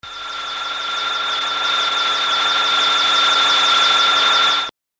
(Funky space sound -- flying saucer.  Large file.)
UFO_Flying_1.wav